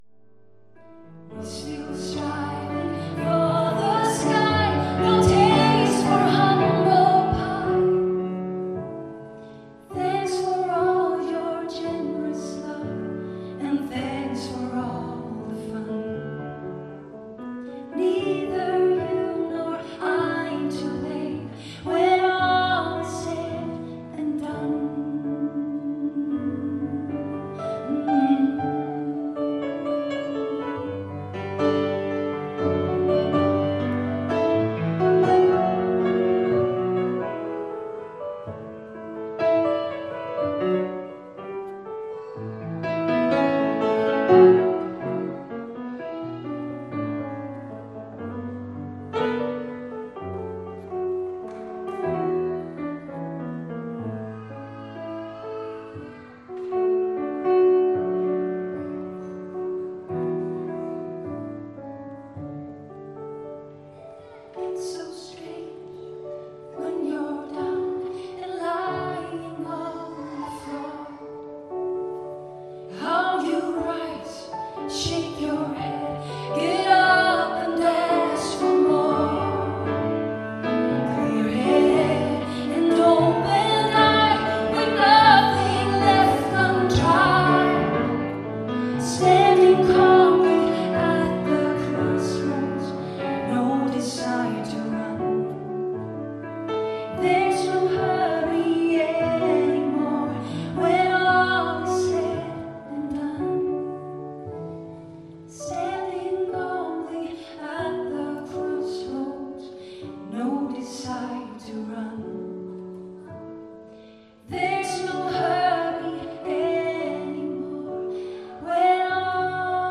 liveinspelning med mobiltelefon.